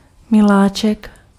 Ääntäminen
Ääntäminen France: IPA: [ɑ̃ʒ] Haettu sana löytyi näillä lähdekielillä: ranska Käännös Konteksti Ääninäyte Substantiivit 1. anděl {m} uskonto Muut/tuntemattomat 2. miláček {m} Suku: m .